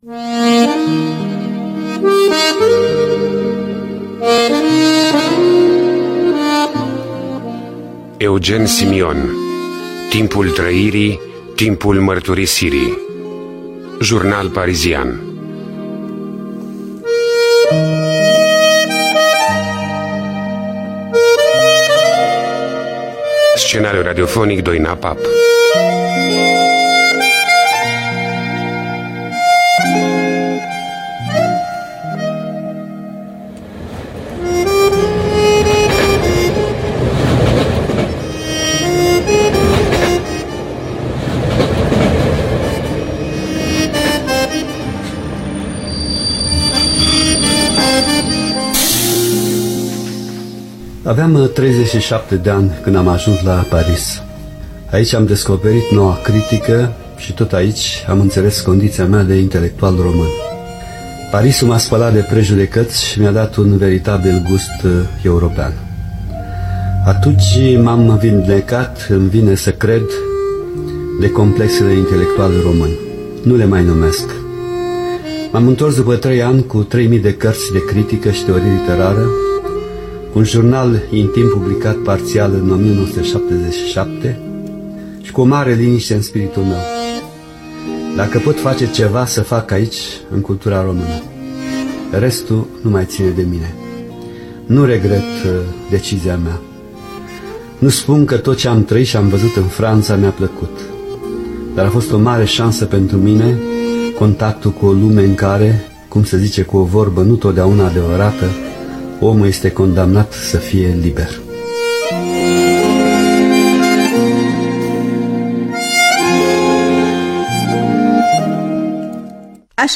Scenariu radiofonic
Cu participarea extraordinară a academicianului Eugen Simion.